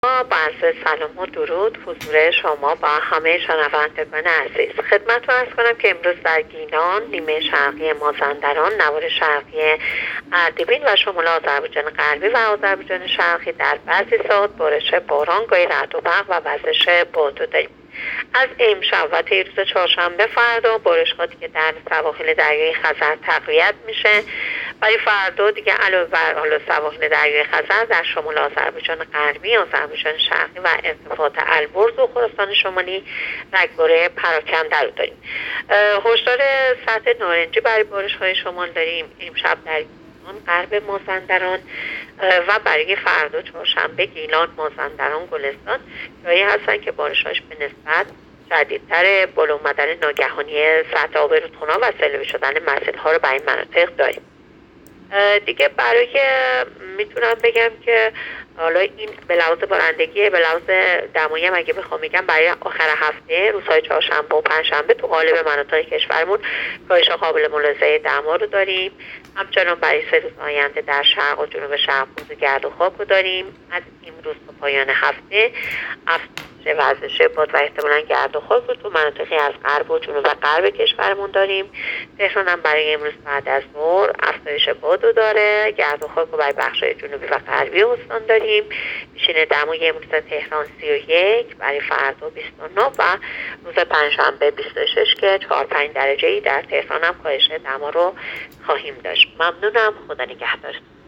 گزارش رادیو اینترنتی پایگاه‌ خبری از آخرین وضعیت آب‌وهوای هشتم مهر؛